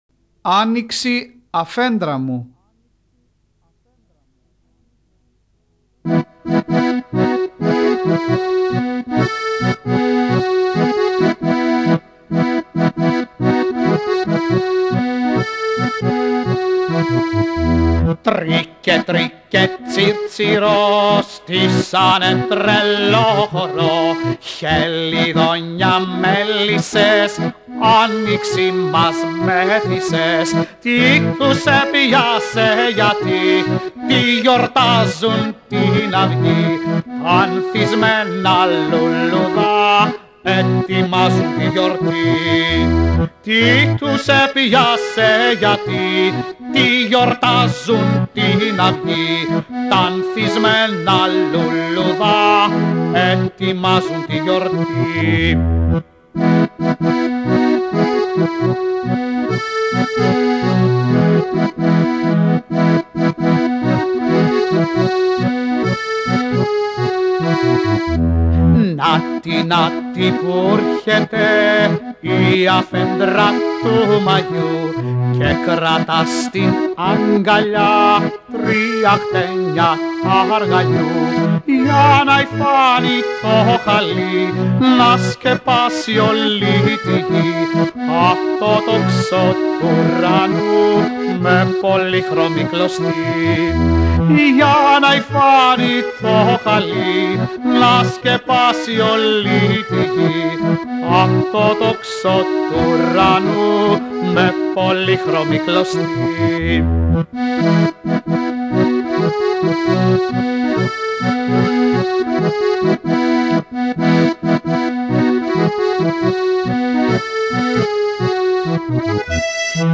Πιάνο